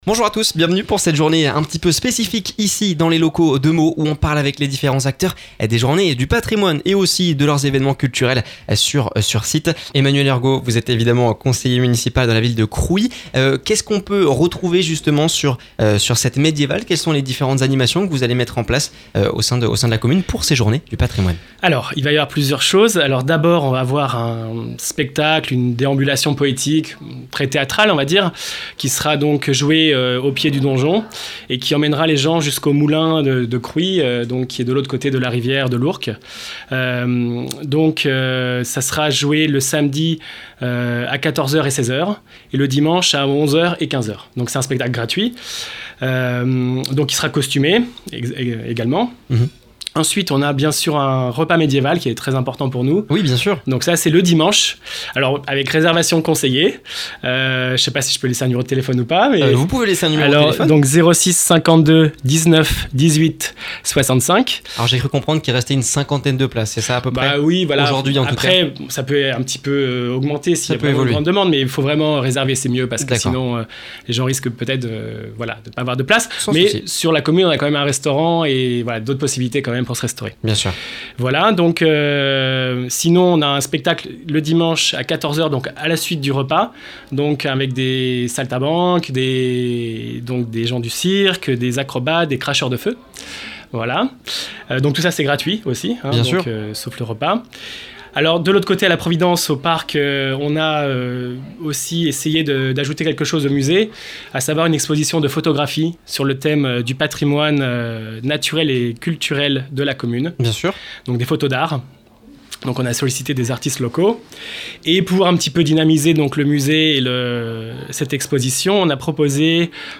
Les collectivités et acteurs locaux du département se sont donnés rendez-vous dans les locaux de Meaux pour une journée spéciale sur Oxygène. L’occasion pour Emmanuel Hergot, conseiller municipal à Crouy-sur-Ourcq d’évoquer les sujets majeurs de la commune pour les journées du patrimoine ce week-end.